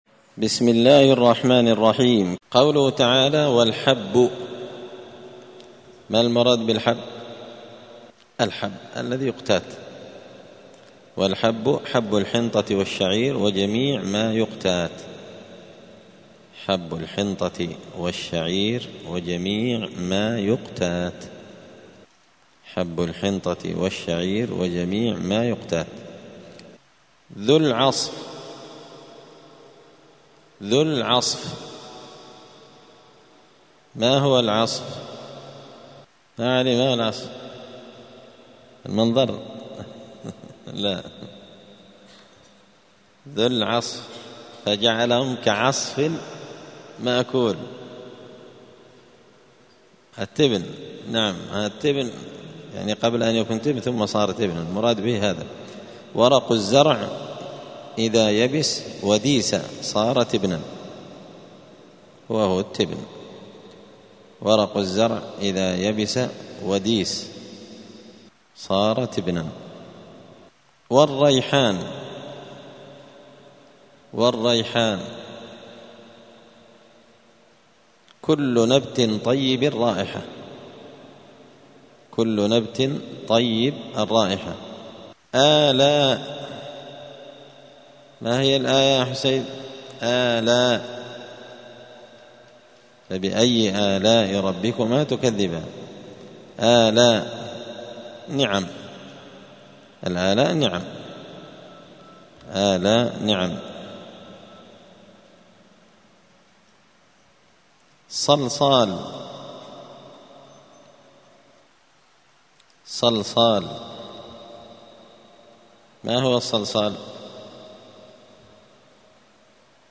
*(جزء الذاريات سورة الرحمن الدرس 179)*